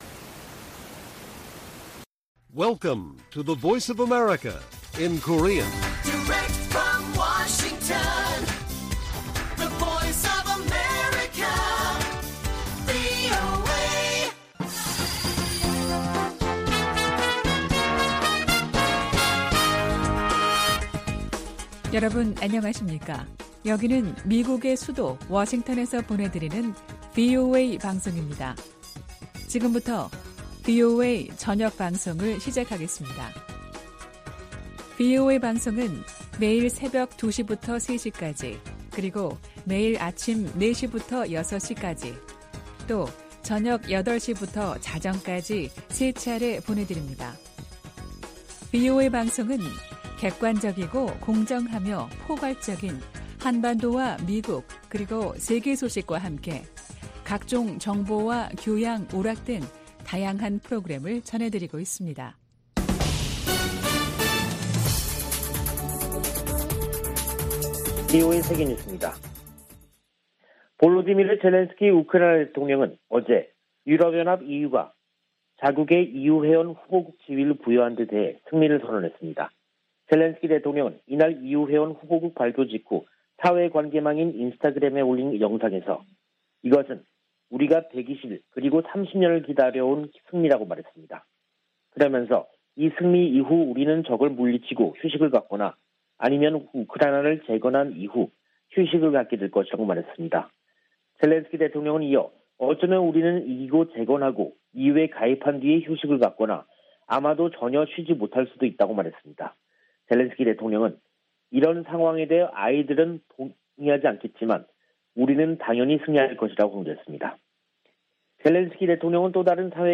VOA 한국어 간판 뉴스 프로그램 '뉴스 투데이', 2022년 6월 24일 1부 방송입니다. 미 하원 군사위 의결 국방수권법안(NDAA)에 한국에 대한 미국의 확장억제 실행 방안 구체화를 요구하는 수정안이 포함됐습니다. 미국의 군사 전문가들은 북한이 최전선에 전술핵을 배치한다고 해도 정치적 의미가 클 것이라고 지적했습니다. 북한은 노동당 중앙군사위원회 확대회의에서 전쟁억제력 강화를 위한 중대 문제를 심의 승인했다고 밝혔습니다.